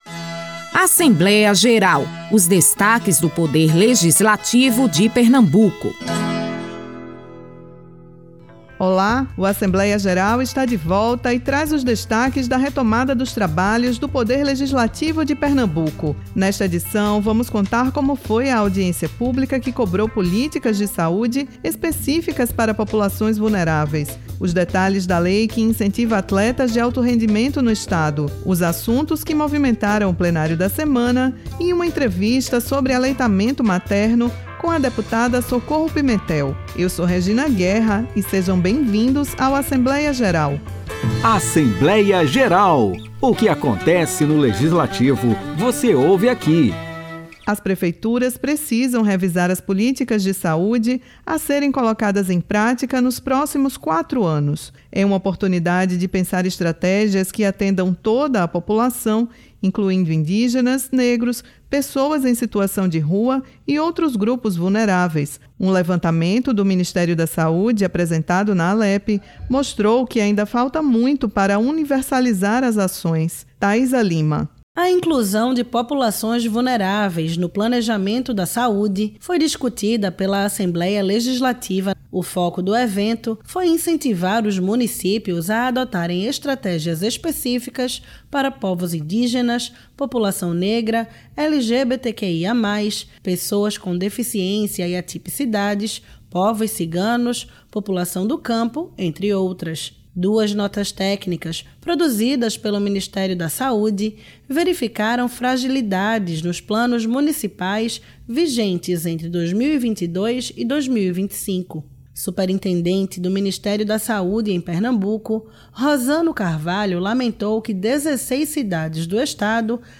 Na entrevista da semana, a deputada Socorro Pimentel (União) fala sobre a importância do aleitamento materno e a instalação de uma sala de amamentação dentro da Alepe, inaugurada há dois anos . O programa Assembleia Geral é uma produção semanal da Rádio Alepe, com os destaques do Legislativo pernambucano.